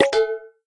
menu_dismiss_01.ogg